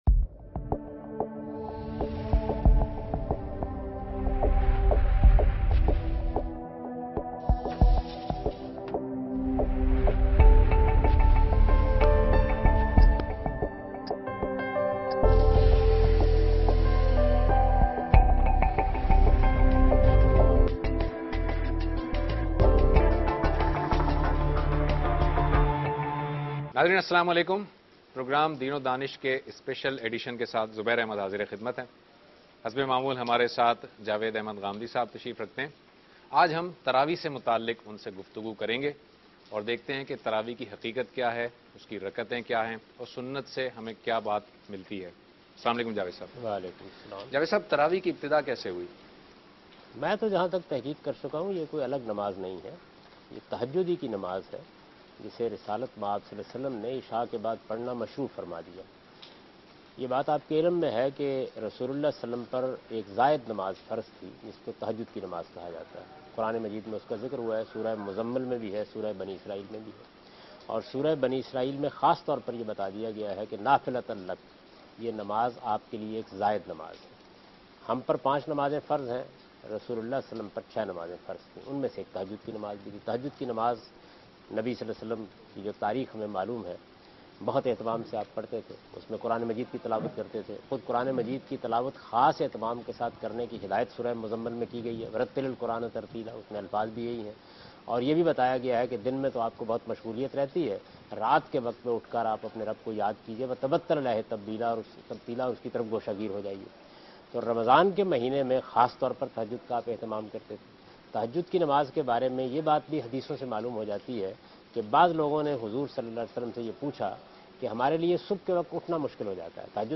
دنیا ٹی وی کے پروگرام دین ودانش میں جاوید احمد غامدی تراویح کی حقیقت کے متعلق گفتگو کر رہے ہیں